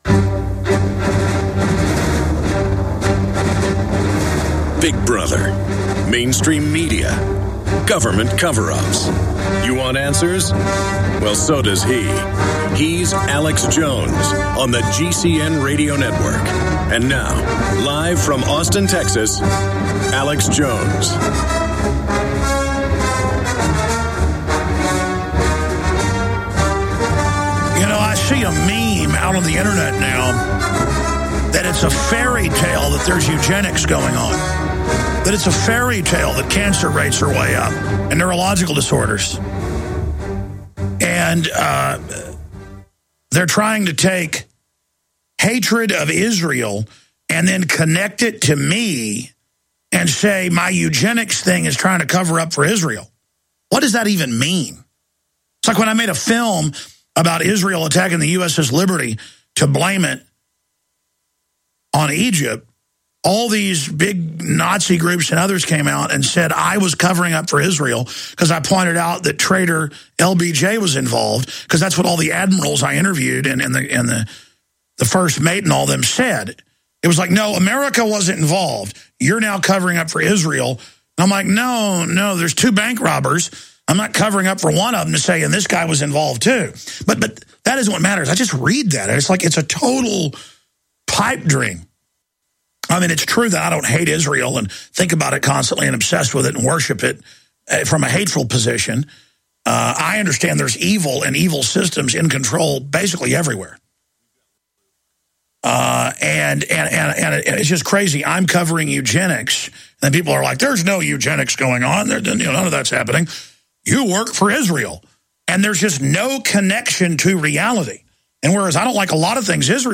Watch Alex's live TV/Radio broadcast.